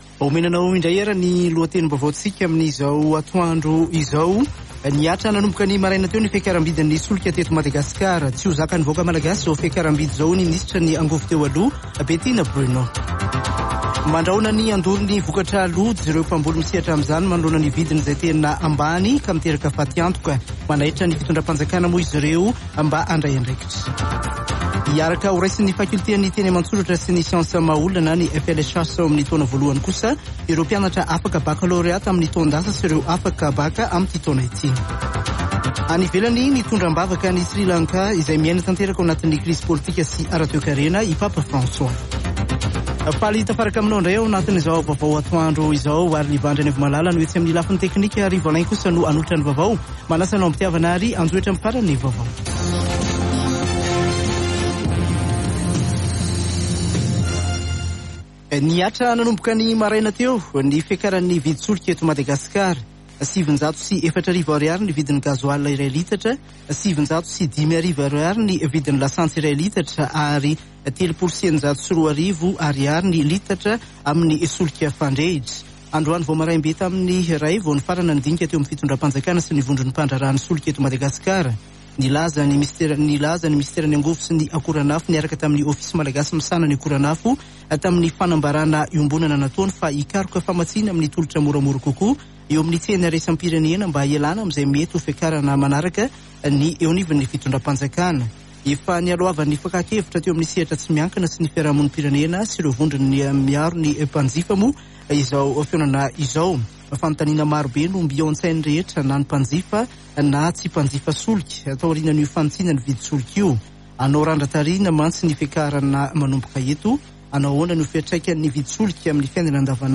[Vaovao antoandro] Alatsinainy 11 jolay 2022